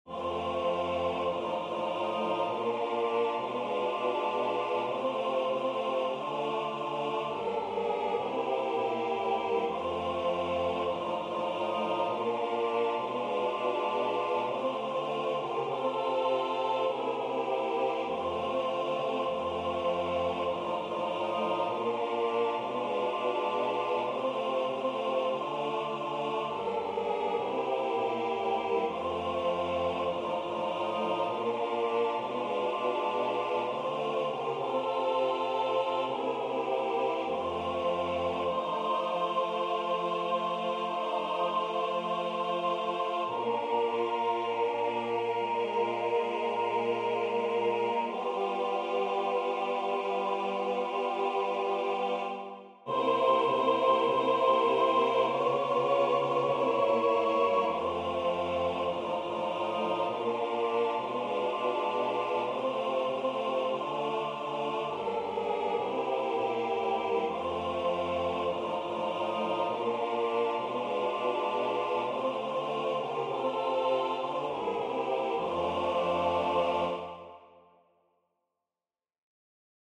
- Œuvre pour choeur à 4 voix a capella
MP3 rendu voix synth.